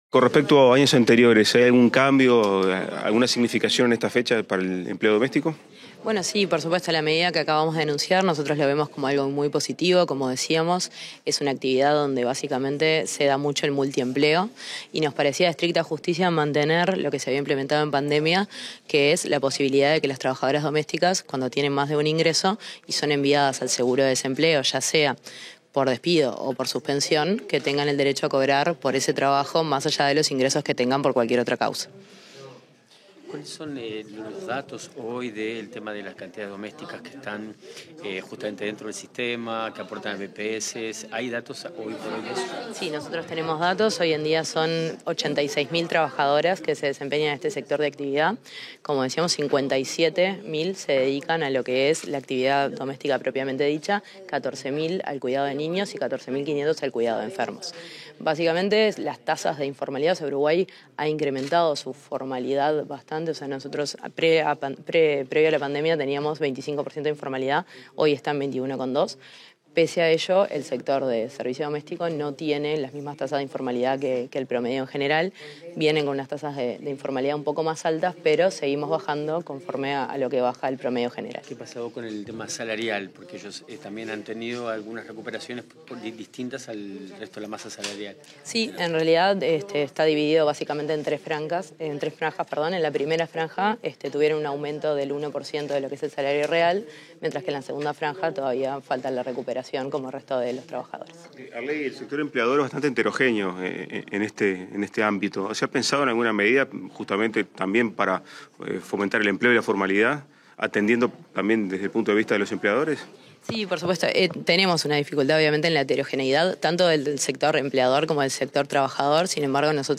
Declaraciones a la prensa de la directora general del Ministerio de Trabajo y Seguridad Social, Valentina Arlegui
Declaraciones a la prensa de la directora general del Ministerio de Trabajo y Seguridad Social, Valentina Arlegui 19/08/2022 Compartir Facebook X Copiar enlace WhatsApp LinkedIn Tras participar en el acto por la conmemoración del Día de la Trabajadora Doméstica, este 19 de agosto, la directora general del Ministerio de Trabajo y Seguridad Social, Valentina Arlegui, realizó declaraciones a la prensa.